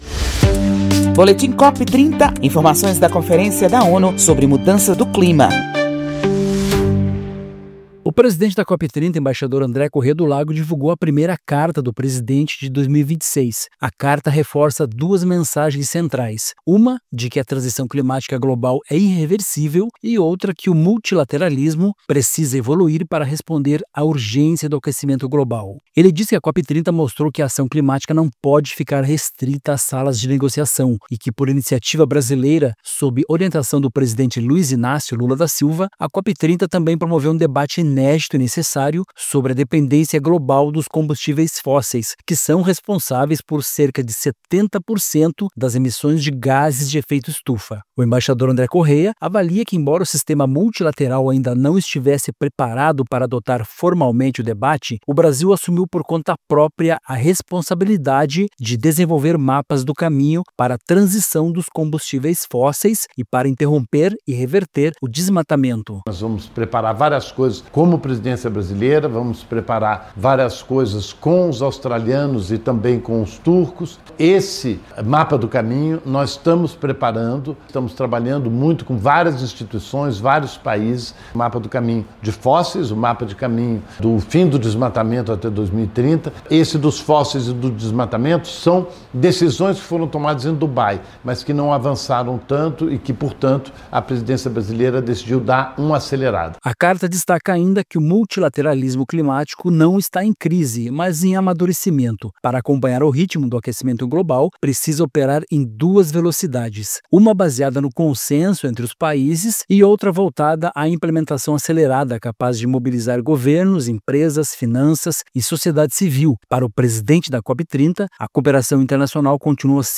Boletim COP30 Brasil Financiamento climático: evitar a mudança do clima seria mais econômico No Acordo de Paris, países ricos prometeram US$ 100 bi/ano para financiar a sustentabilidade em nações em desenvolvimento.